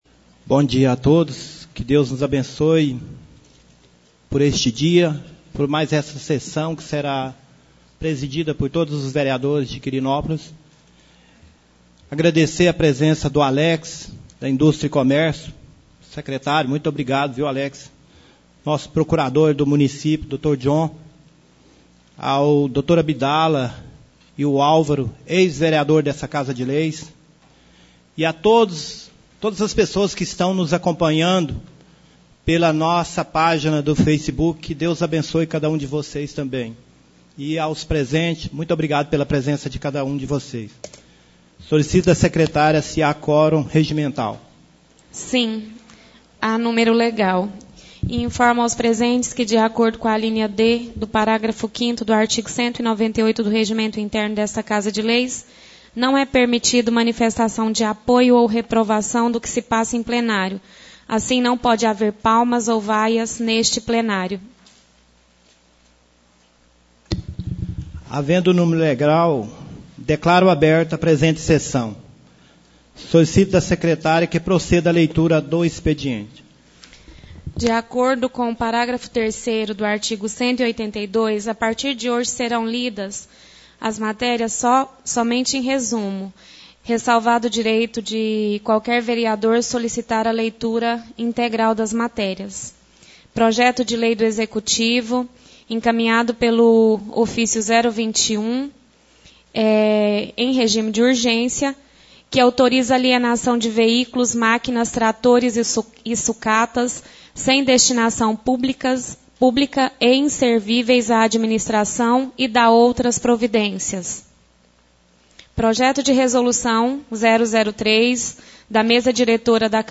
2ª Sessão ordinária do mês de Abril 2017